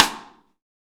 Index of /90_sSampleCDs/Best Service - Real Mega Drums VOL-1/Partition D/AMB KIT 02EC